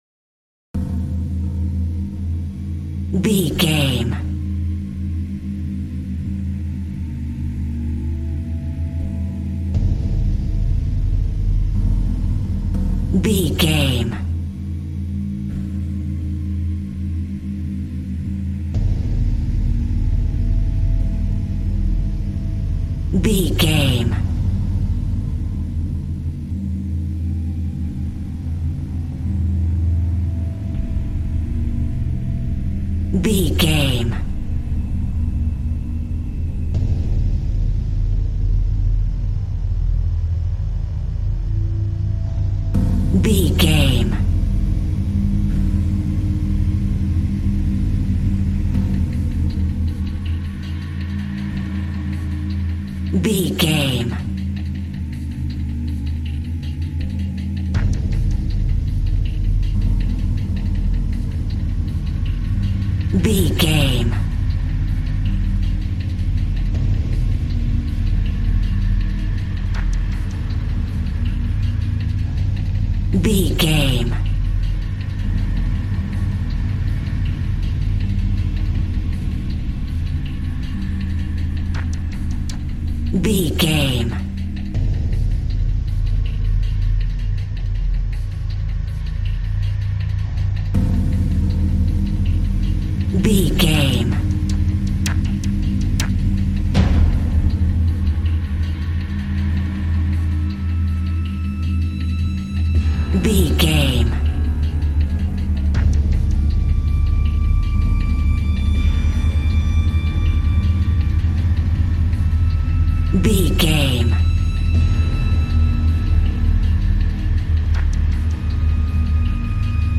A scary horror track full of suspense.
Aeolian/Minor
piano
synthesiser